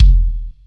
Kicks
SW KCK5.wav